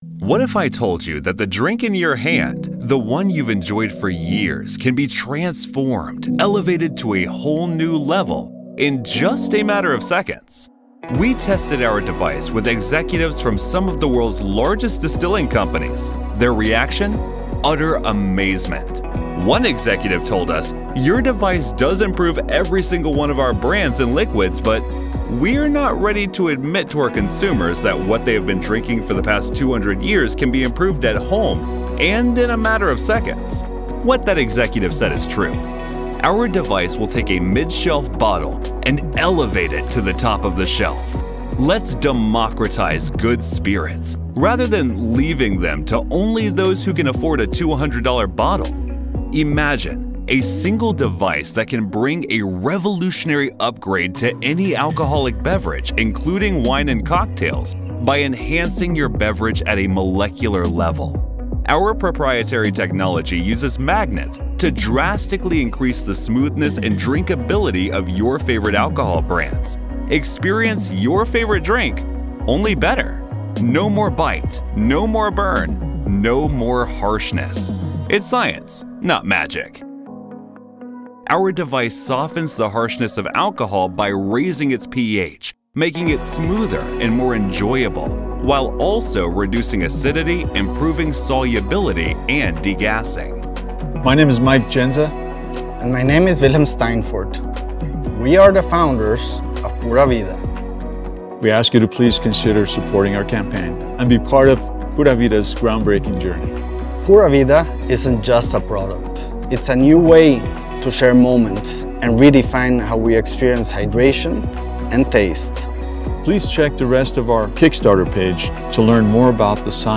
Llamativo, Versátil, Cálida, Amable, Empresarial
Explicador